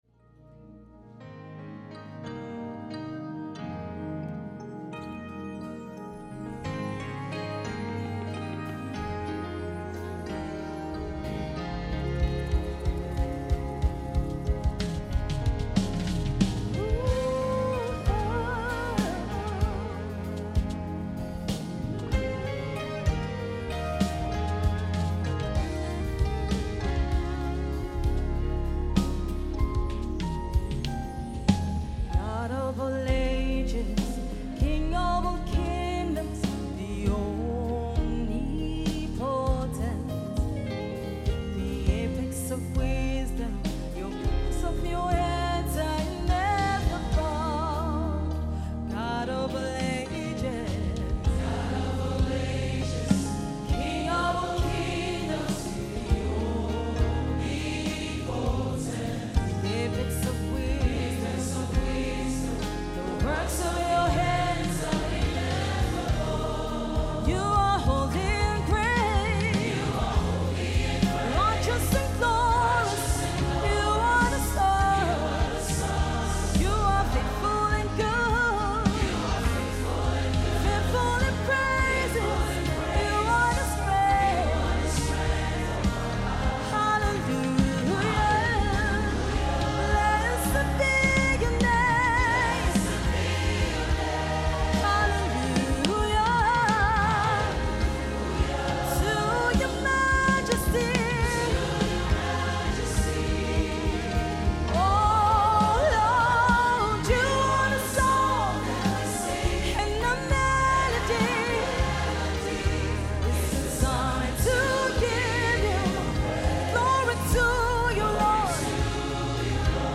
Lyrics, Praise and Worship